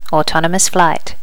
auto flight.wav